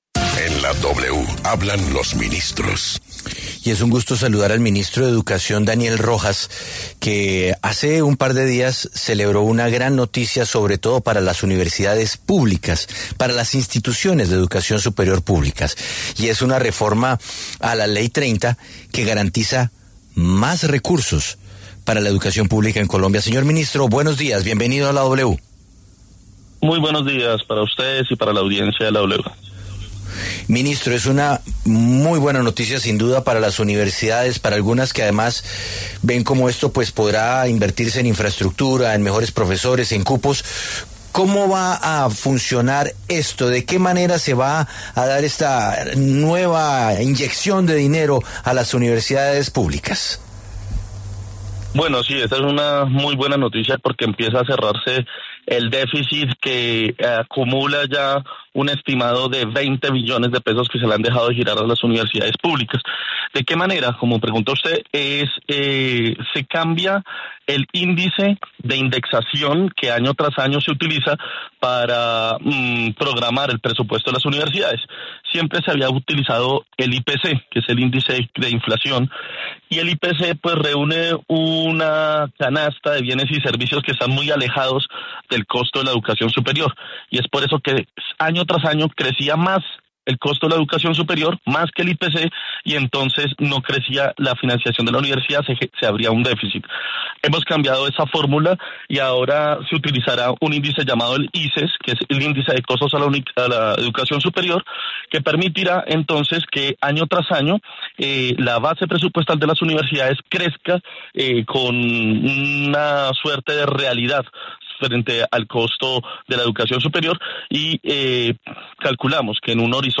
El ministro de Educación, Daniel Rojas, explicó en la W que la reforma a la Ley 30 busca cerrar el déficit histórico del sistema y garantizar un crecimiento real de los recursos para la educación superior.